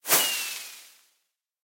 launch.ogg